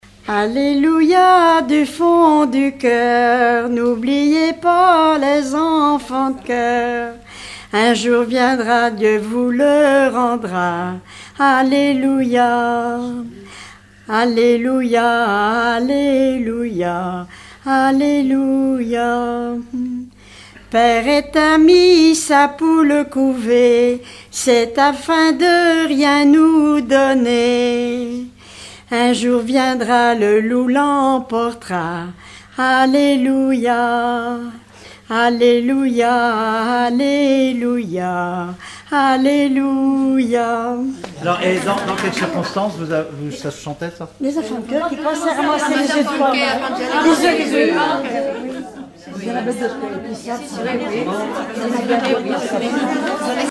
circonstance : quête calendaire
Regroupement de chanteurs du canton
Pièce musicale inédite